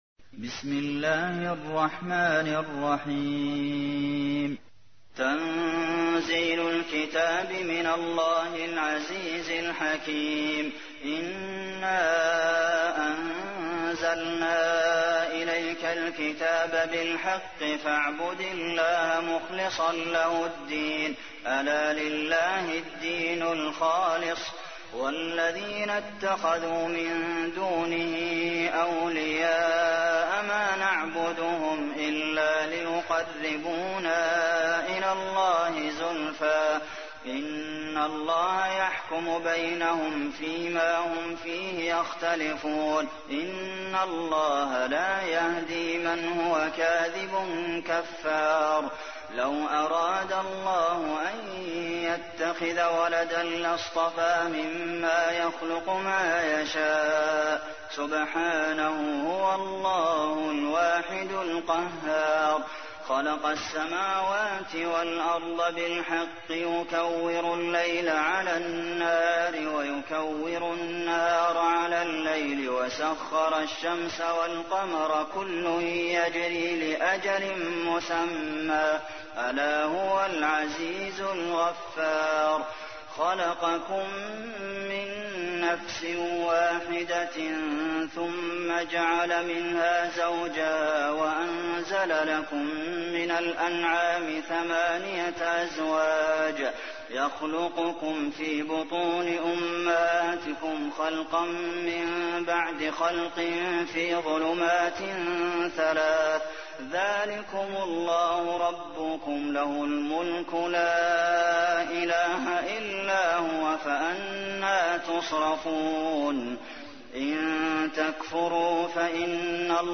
تحميل : 39. سورة الزمر / القارئ عبد المحسن قاسم / القرآن الكريم / موقع يا حسين